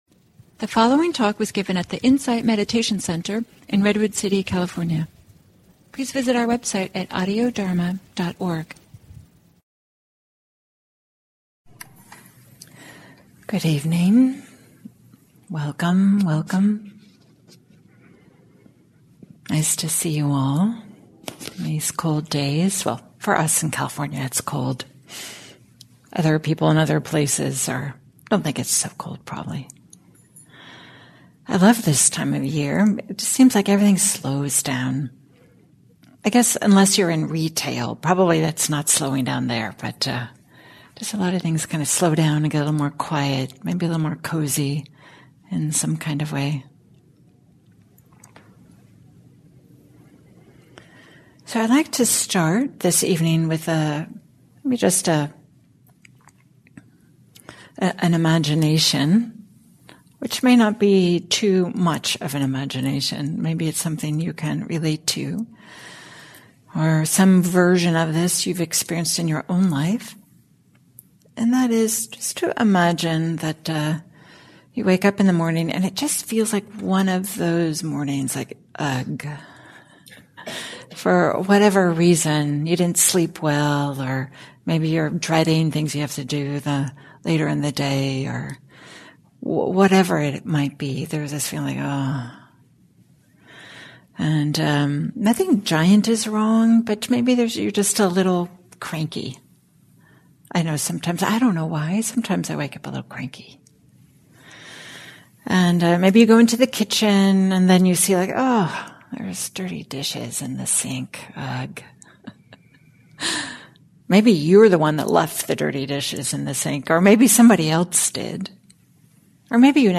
Dharma talks